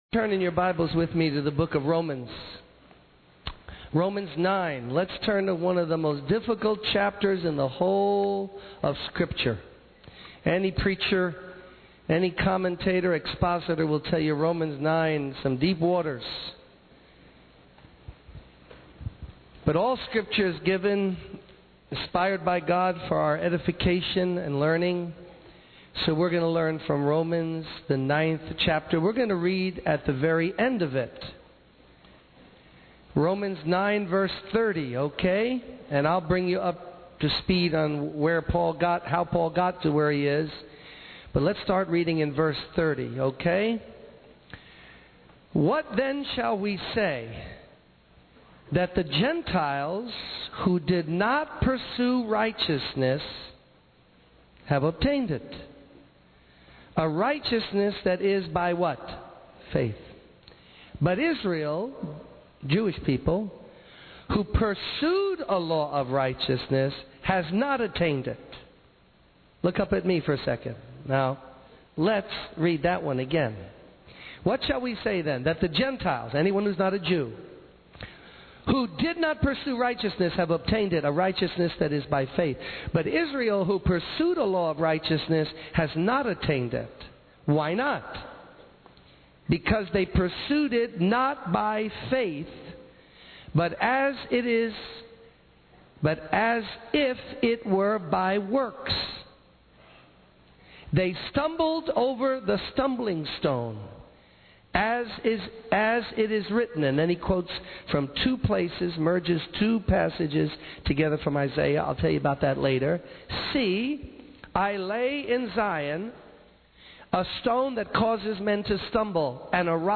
In this sermon, the preacher discusses the concept that some good things can have a negative impact on people.